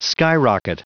Prononciation du mot : skyrocket